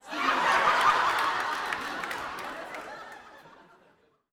Audience Laughing-02.wav